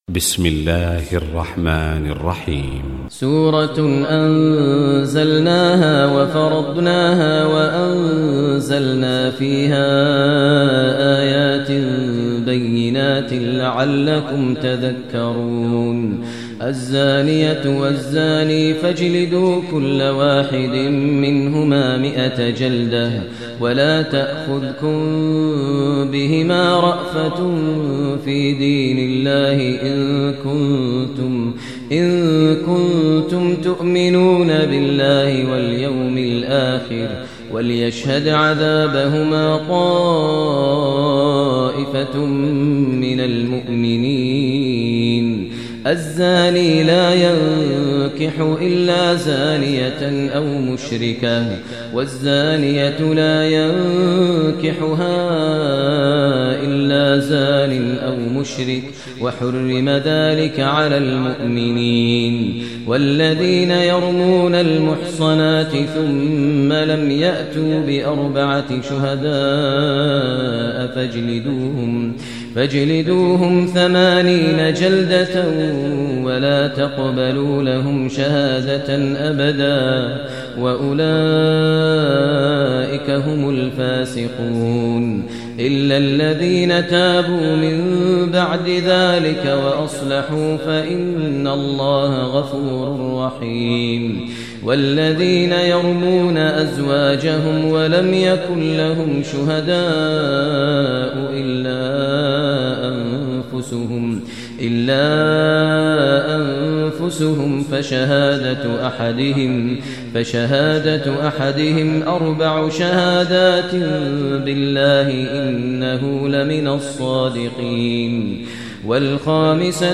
Surah Nur Recitation by Sheikh Maher Mueaqly
Surah Nur, listen online mp3 tilawat / recitation in Arabic recited by Imam e Kaaba Sheikh Maher al Mueaqly.